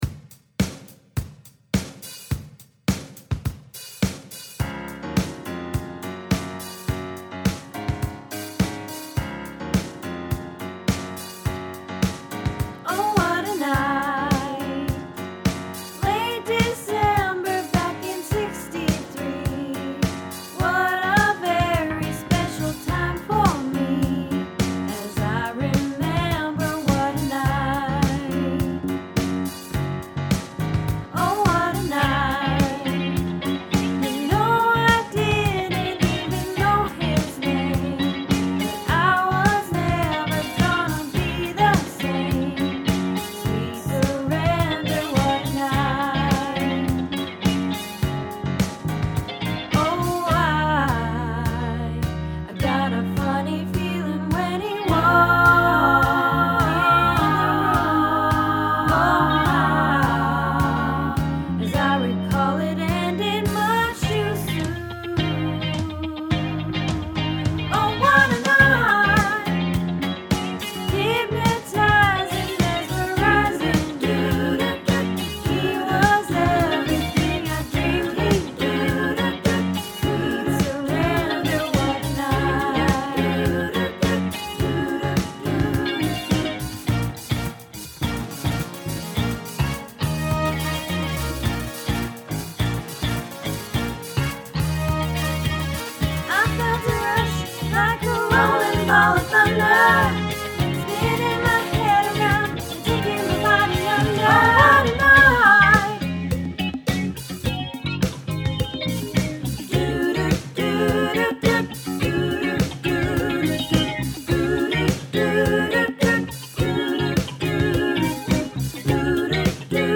Oh What A Night - Practice